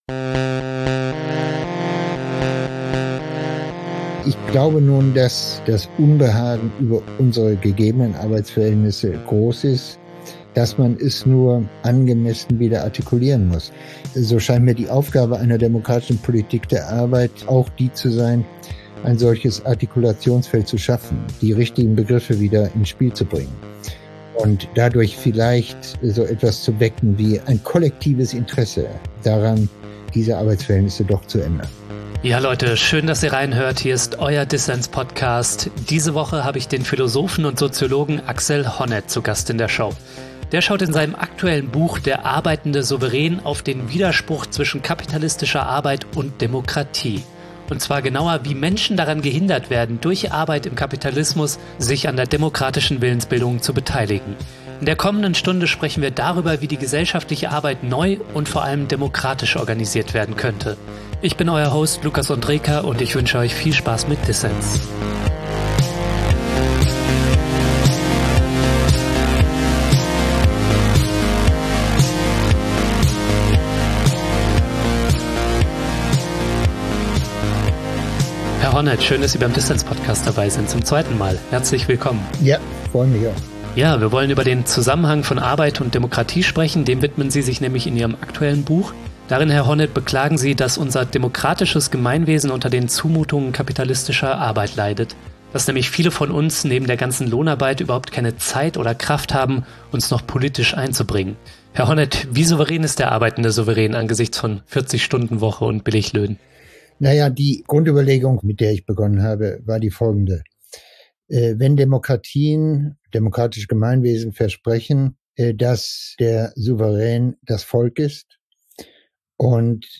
Prekäre Beschäftigung hindert viele Menschen daran, an der demokratischen Willensbildung teilzunehmen, sagt Axel Honneth. In seinem Buch "Der arbeitende Souverän" untersucht der Soziologe den Widerspruch zwischen Arbeitsalltag und demokratischem Anspruch. Ein Gespräch über Plackerei, Post-Demokratie und den Kampf für eine bessere Arbeitswelt.